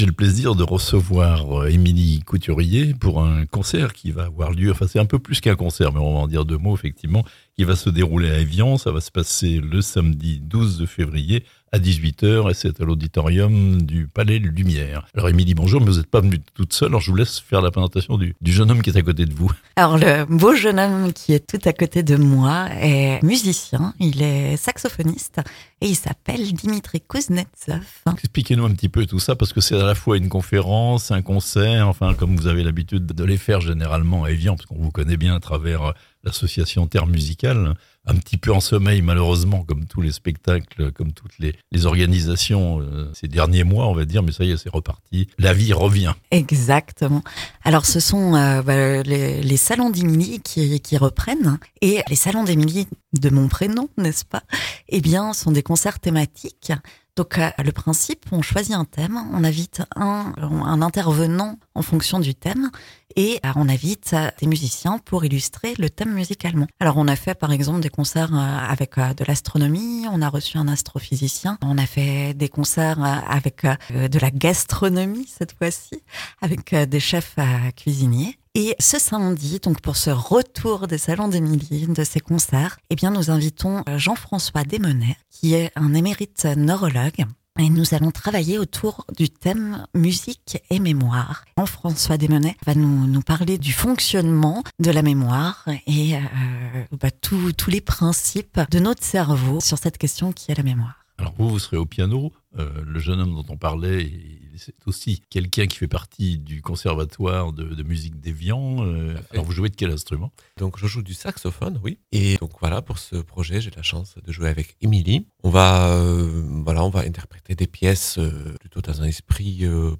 Au micro La Radio Plus